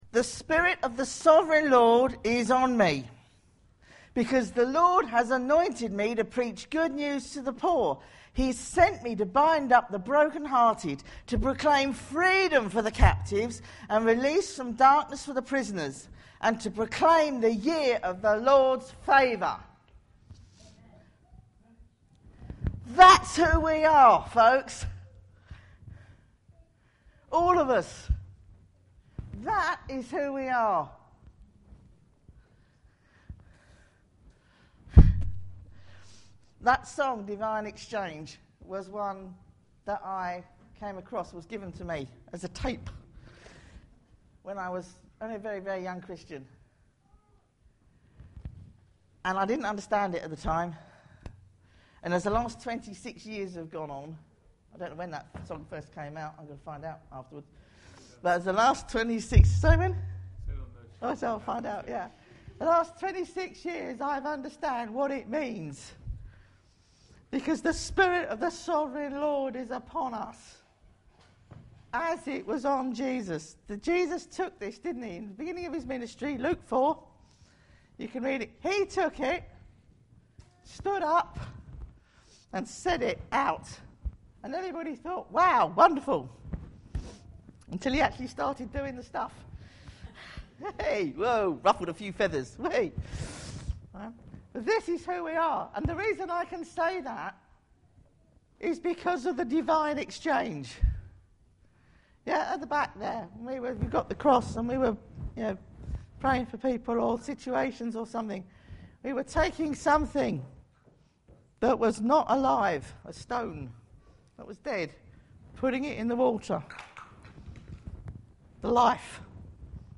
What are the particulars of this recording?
Isaiah 61 Service Type: Sunday Morning « What voices are we listening to?